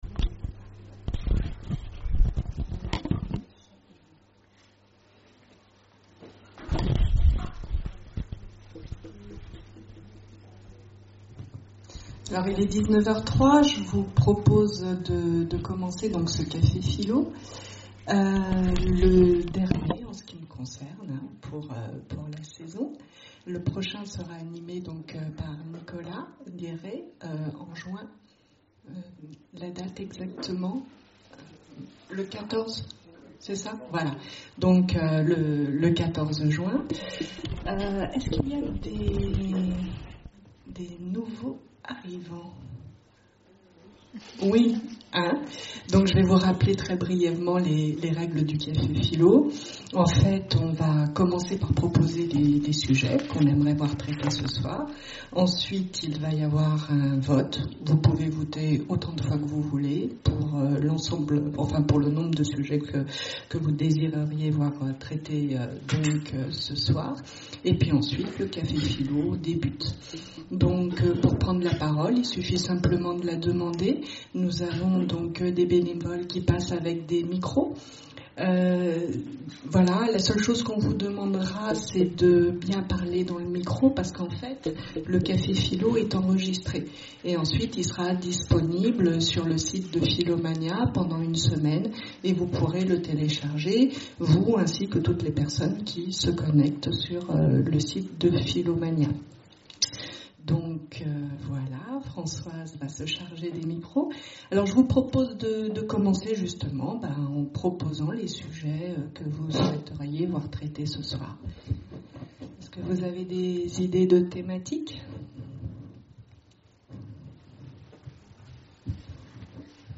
Conférences et cafés-philo, Orléans
CAFÉ-PHILO PHILOMANIA Est-ce qu’il y a des guerres justes ?